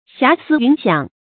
霞思云想 注音： ㄒㄧㄚˊ ㄙㄧ ㄧㄨㄣˊ ㄒㄧㄤˇ 讀音讀法： 意思解釋： 見「霞思天想」。